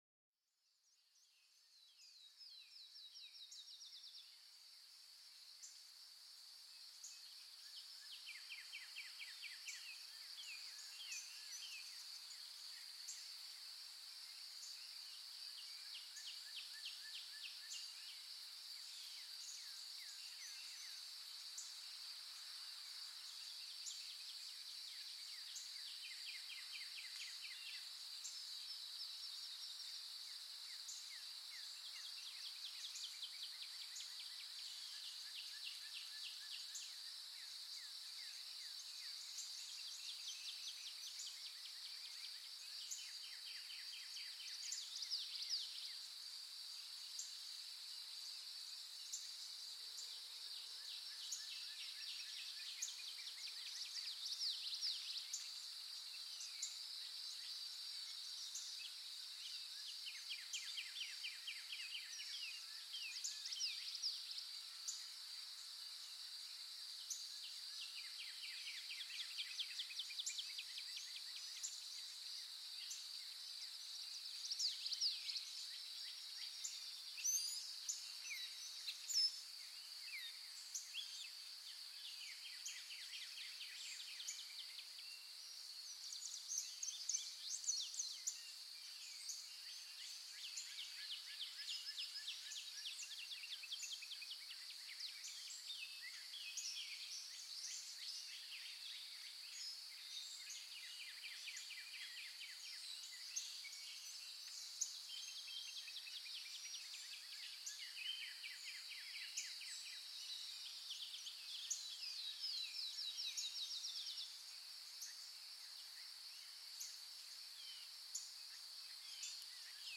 Sinfonía Natural: El Canto de los Pájaros
Sumérgete en un mundo vibrante y lleno de vida, donde los sonidos de la naturaleza y los pájaros celebran la belleza de la vida, este episodio te lleva en un viaje auditivo a través de un bosque lleno de vida o un parque sereno, donde el canto melódico de los pájaros se mezcla con el susurro de las hojas y el suave fluir de un arroyo cercano, déjate llevar por la alegría y la tranquilidad que proporcionan los sonidos de la naturaleza y los pájaros, una invitación a apreciar la armonía y belleza